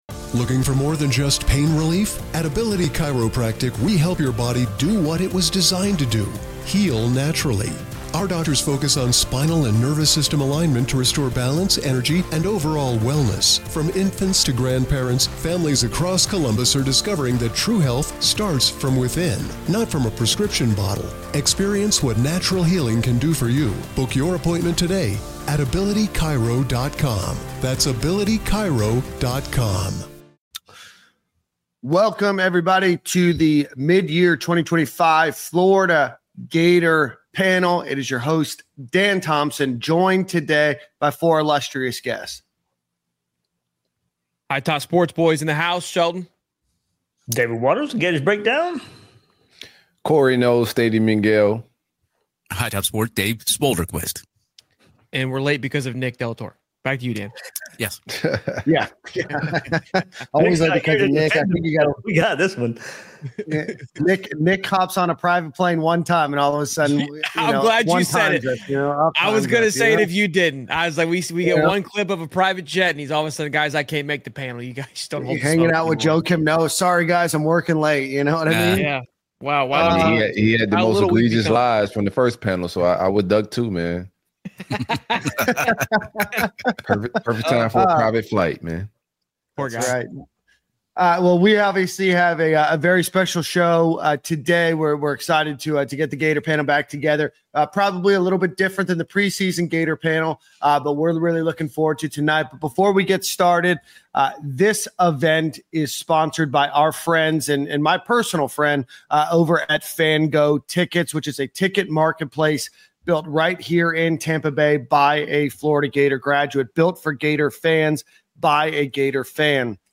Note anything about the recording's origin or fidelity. Stadium & Gale, Gators Breakdown, and Hightop Sports is live with instant reaction to Billy Napier’s firing, where Florida stands mid-season, and who should be next. We’re focusing on what's next for the Florida Gators, sorting real targets from message-board myths, and mapping the hire timeline.